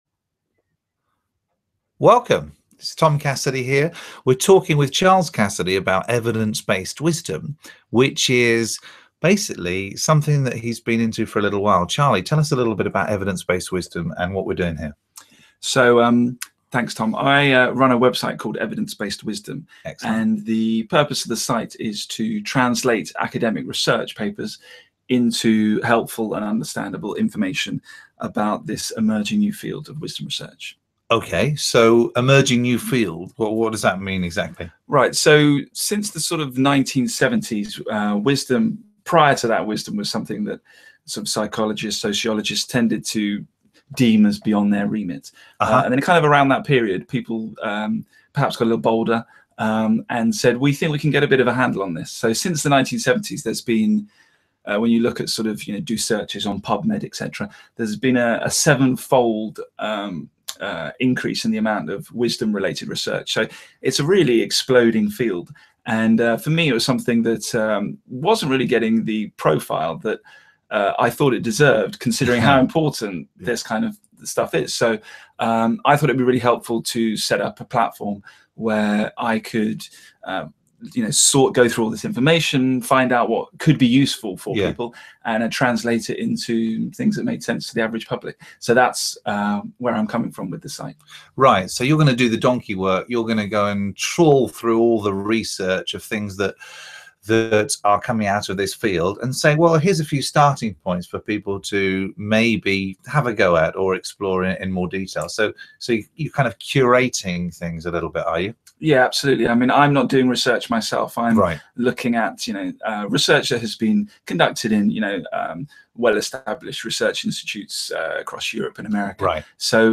The Evidence-based Wisdom Screencast Series is a collection of short conversations discussing 10 of the major ideas and themes emerging from the field of Wisdom Research.